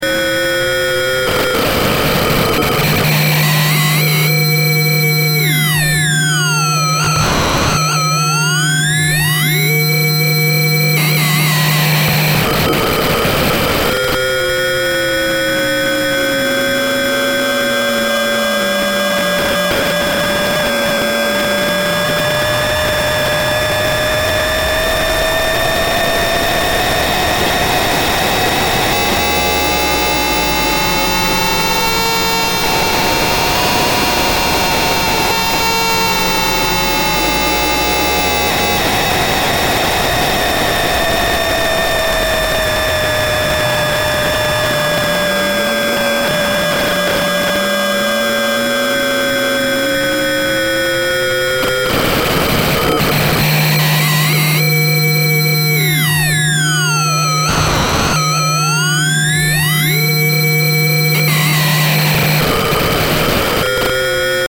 Chaotic Equation for Modular Synthesizer , 2009 Modular synthesizer as analog computer, running the Logistic Difference Equation ( Xn+1 = XnR(1 - Xn) ), which models population growth in an ecosystem with a given carrying capacity. As the value of R is swept, the equation moves through stable and chaotic states, causing the timbre to vary in complexity.
Computed on the 5U modular system at Mills College.
chaos_snippet.mp3